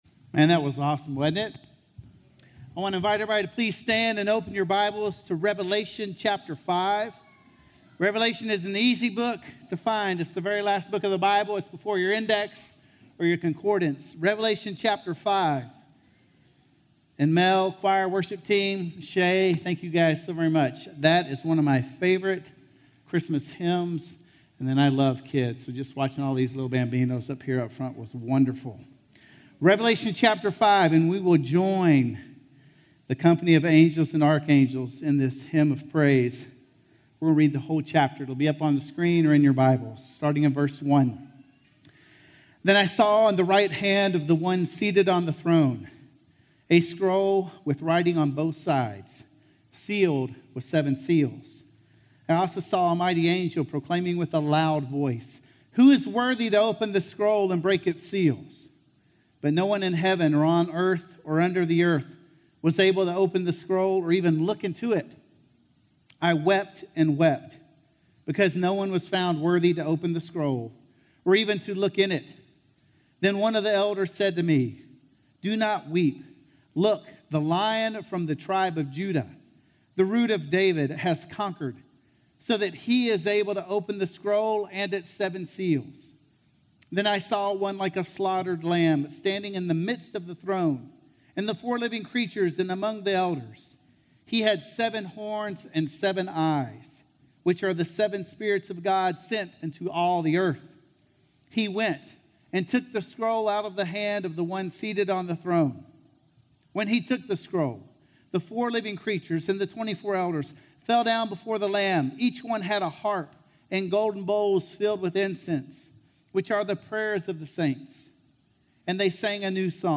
Worthy - Sermon - Woodbine